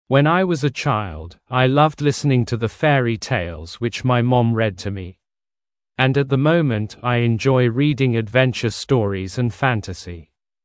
Произношение:
[Уэн ай уоз э чайлд, ай лавд лисенинг ту зэ фэри тейлз уич май мом рид ту ми. Энд эт зэ моумент ай инджой ридин эдвэнчэр сториc энд фэнтэзи].